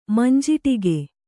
♪ manjiṭige